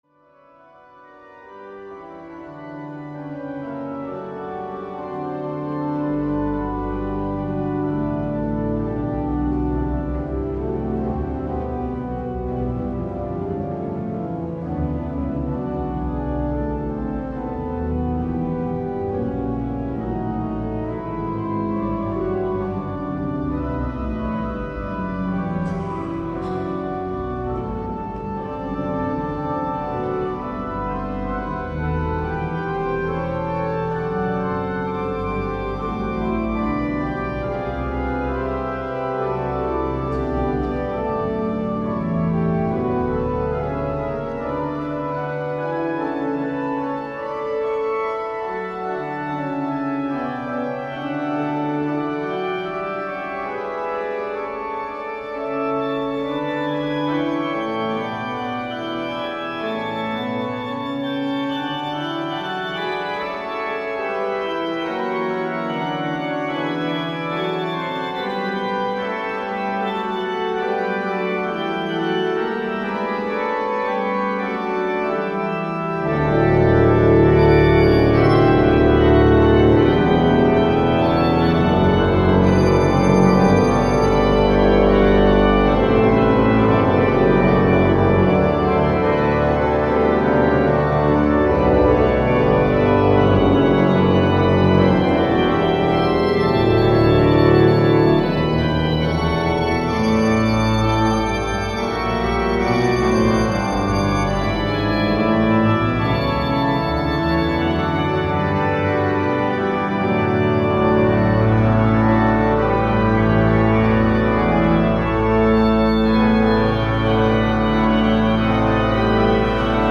Die Orgel der Herz-Jesu-Kirche mit pneumatischen Kegelladen hat über 2.140 Orgelpfeifen, die sich auf 37 Register mit 3 Manualen und Pedal verteilen.
Klangbeispiel der Jehmlich Orgel (Fuge in D-Dur